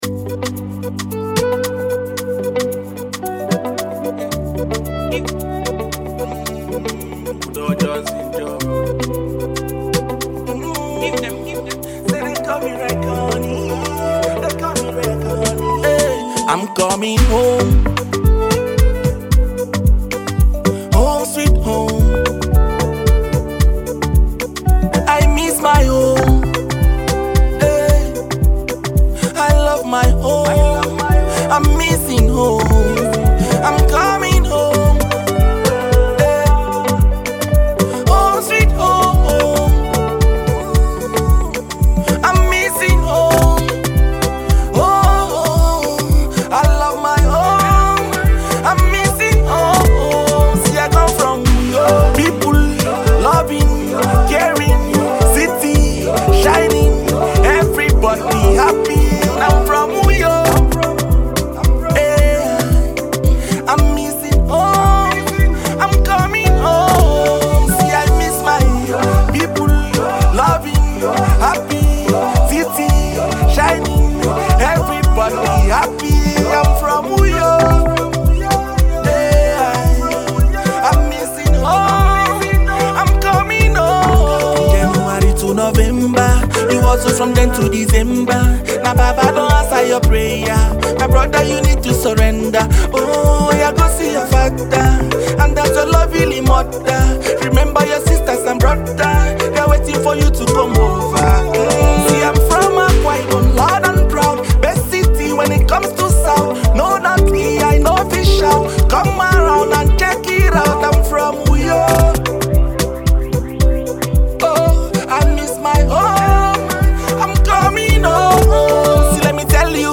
great Afro Beats tunes with the Ibibio /Efik infusion